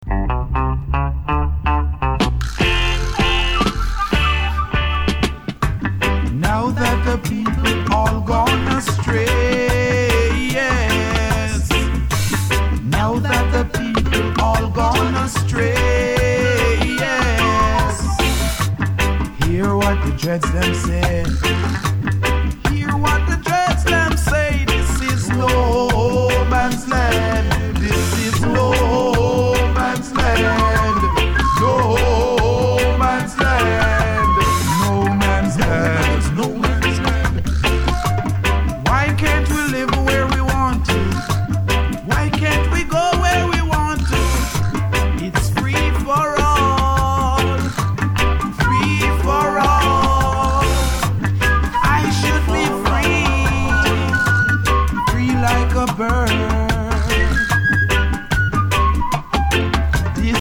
seventies reggae tracks
veteran reggae singer
recorded at various studios in Jamai